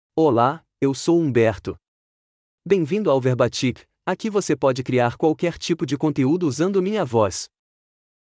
Humberto — Male Portuguese (Brazil) AI Voice | TTS, Voice Cloning & Video | Verbatik AI
HumbertoMale Portuguese AI voice
Humberto is a male AI voice for Portuguese (Brazil).
Voice sample
Humberto delivers clear pronunciation with authentic Brazil Portuguese intonation, making your content sound professionally produced.